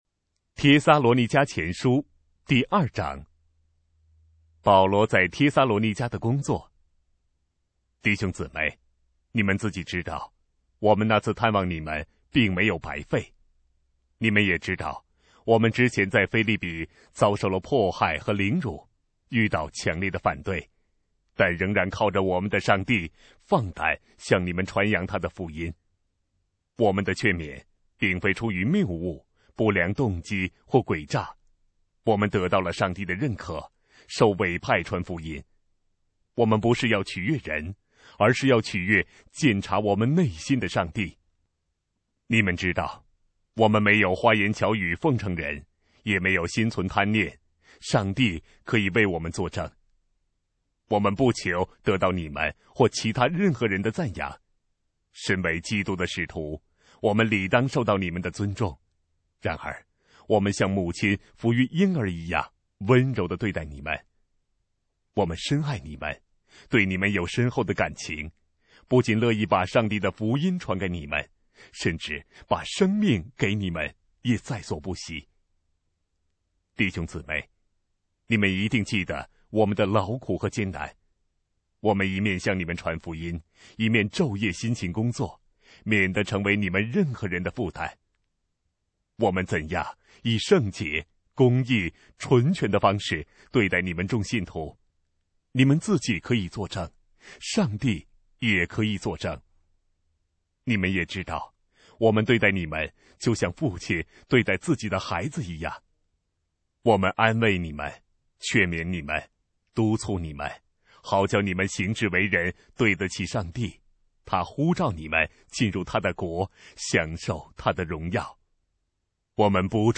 当代译本朗读：帖撒罗尼迦前书